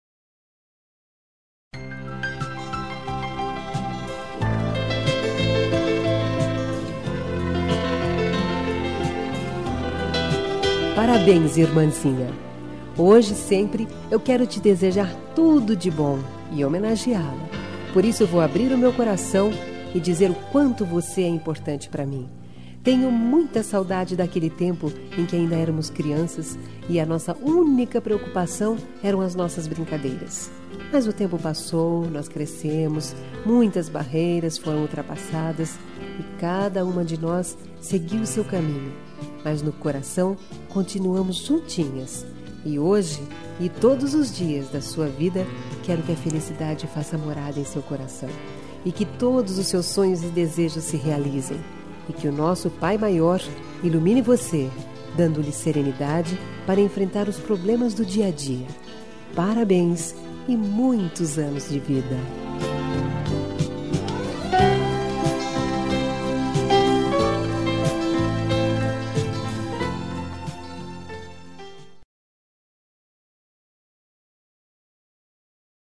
Aniversário de Irmã -Voz Feminina – Cód: 013845